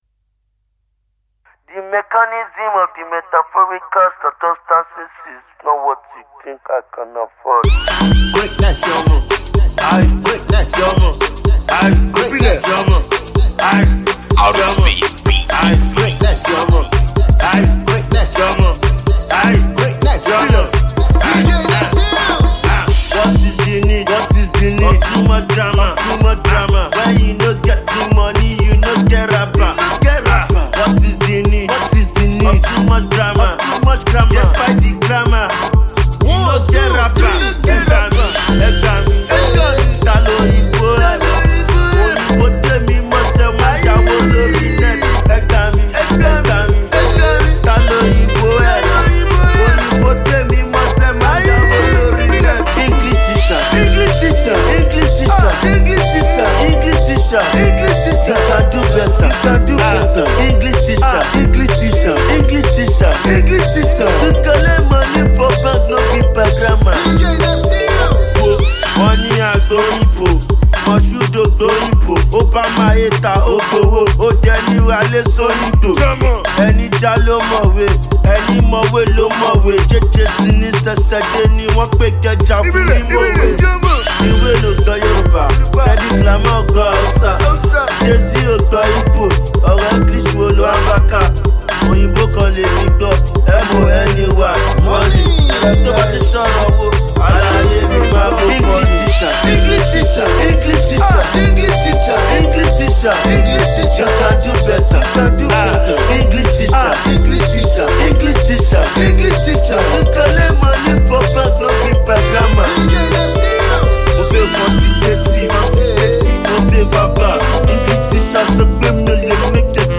street-hop
groovy joint